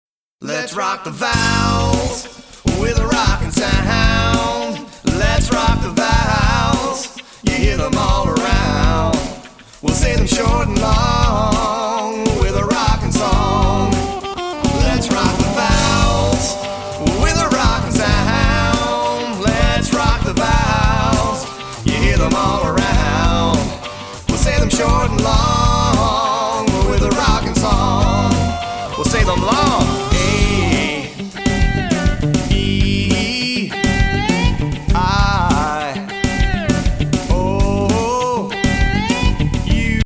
Filled from beginning to end with rockin’ movement tunes